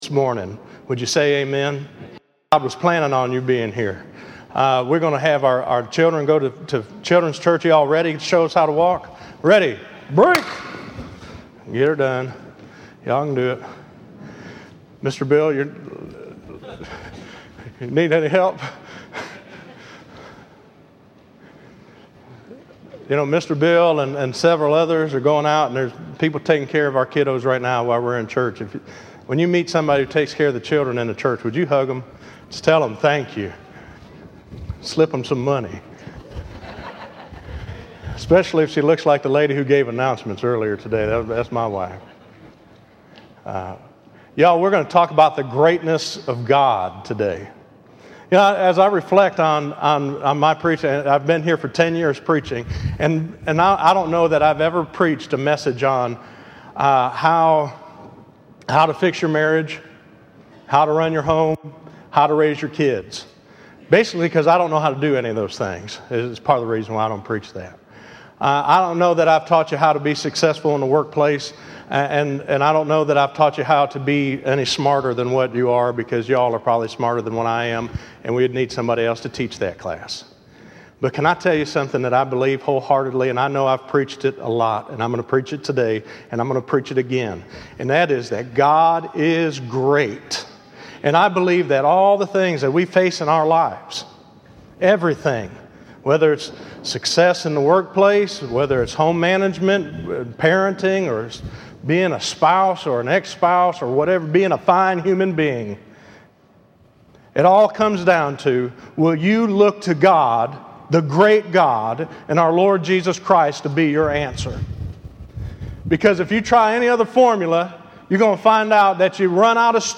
Listen to The Greatness of God - 07_12_15_sermon.mp3